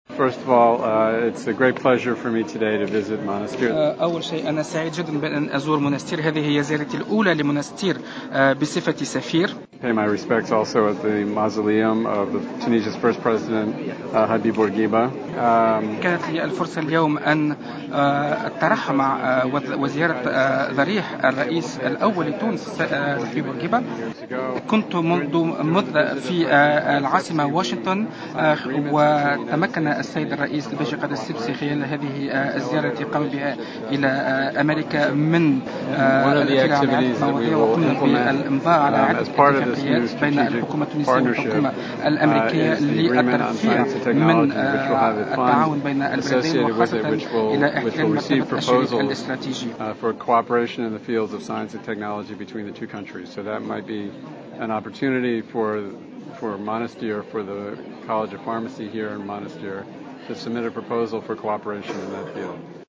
وأوضح في تصريح لـ"جوهرة أف ام" على هامش زيارة أداها إلى روضة آل بورقيبة بولاية المنستير اليوم، أن تونس التي أصبحت شريكا استراتيجيا للولايات المتحدة، أمضت على اتفاق للتعاون في مجال البحث العلمي الذي سيخصص لتلقي طلبات تعاون مع الطرف الأميركي ويمكن أن يكون هذا الصندوق فرصة للمنستير وخاصة لكلية الصيدلة بها للتقدم للحصول على تعاون مع جامعات أميركية نظيرة لهذه الكلية.